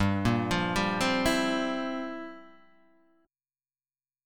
Gm13omit5 chord {3 1 3 0 1 0} chord